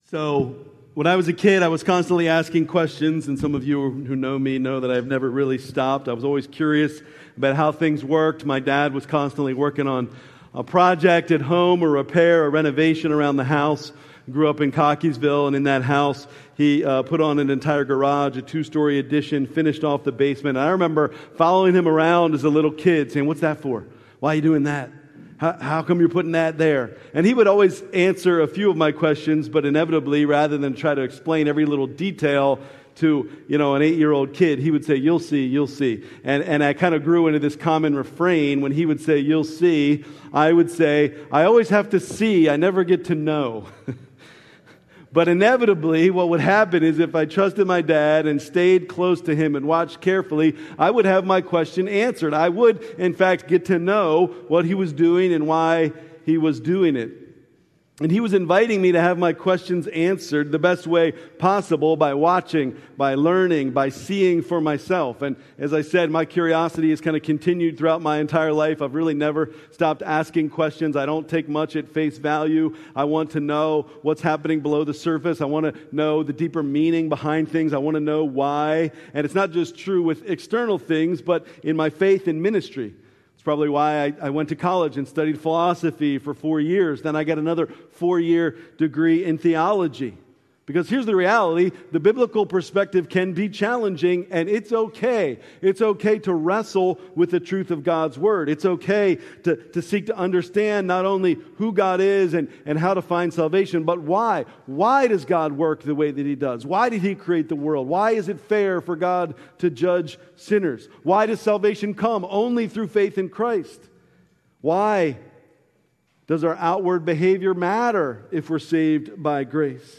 October 5, 2025 Living Hope Church Worship Service